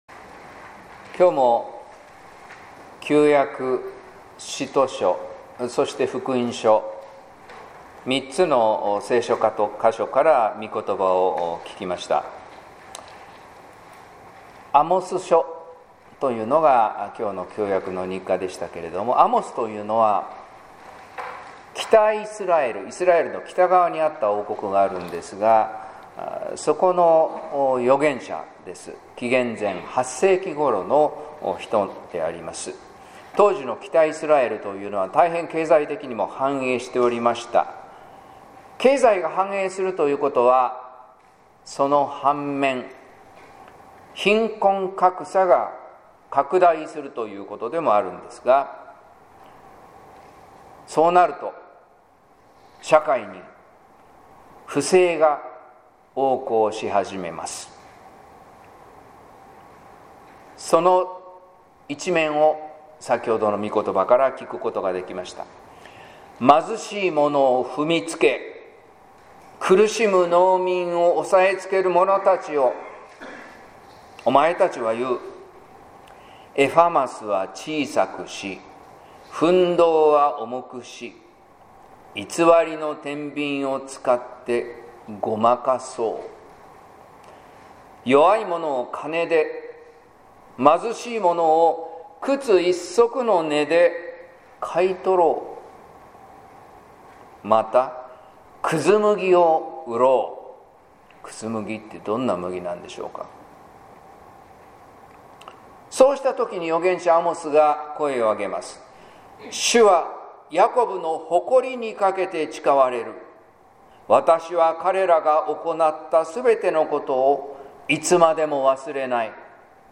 説教「思いもよらないたとえ」（音声版）